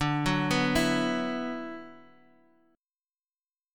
D6add9 Chord
Listen to D6add9 strummed